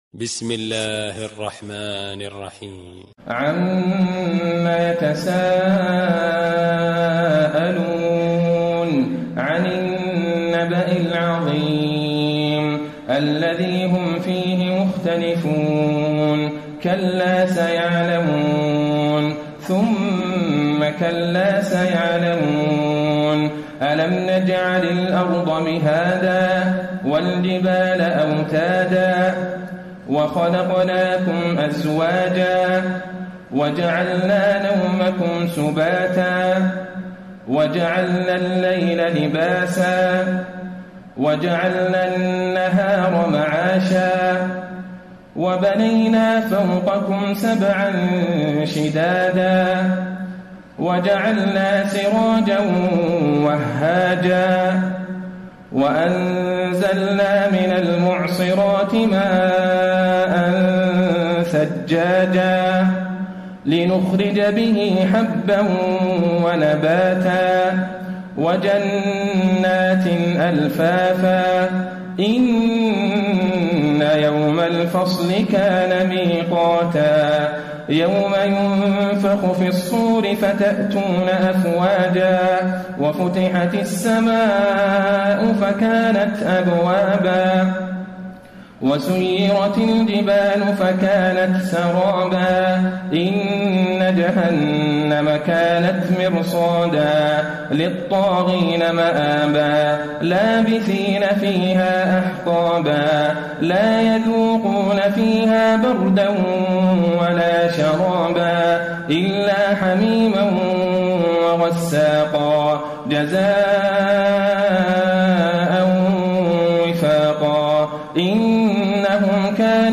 تراويح ليلة 29 رمضان 1434هـ من سورة النبأ الى البلد Taraweeh 29 st night Ramadan 1434H from Surah An-Naba to Al-Balad > تراويح الحرم النبوي عام 1434 🕌 > التراويح - تلاوات الحرمين